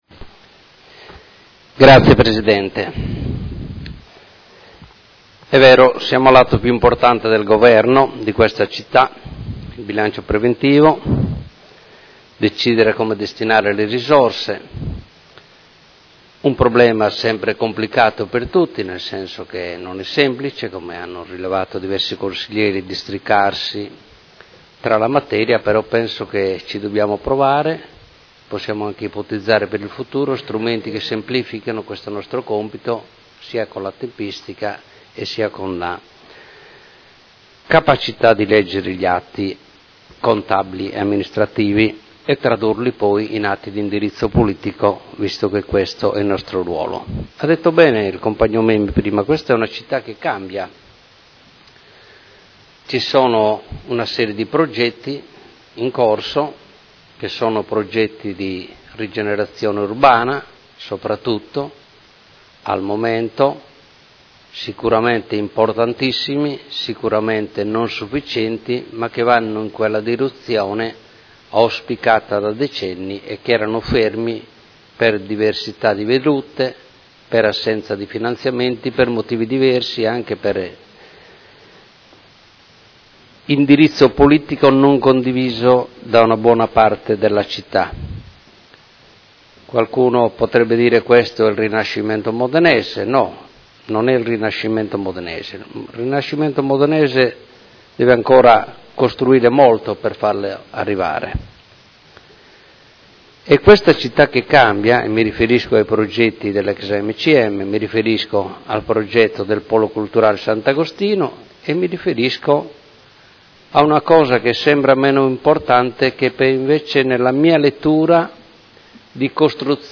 Seduta del 26 gennaio. Bilancio preventivo: Dibattito